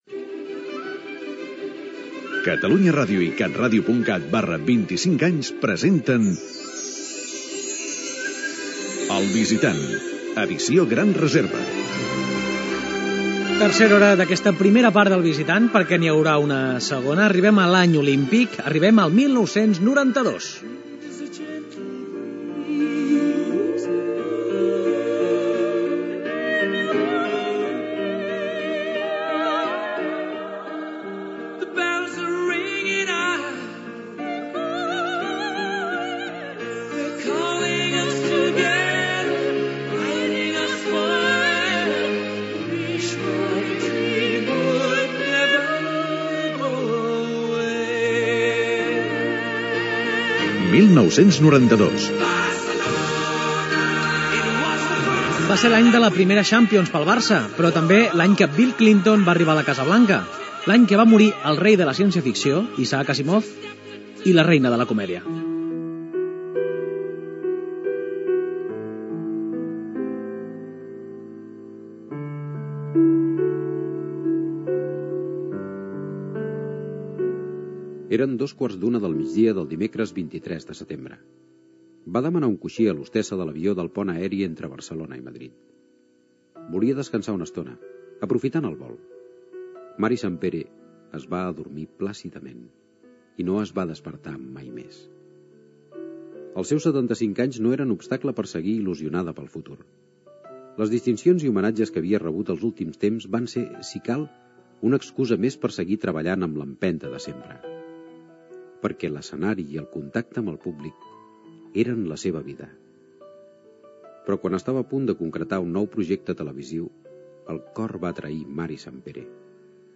Identificació del programa, l'any 1992: resum de fets de l'any i la biografia de Mary Santpere, amb declaracions seves Gènere radiofònic Entreteniment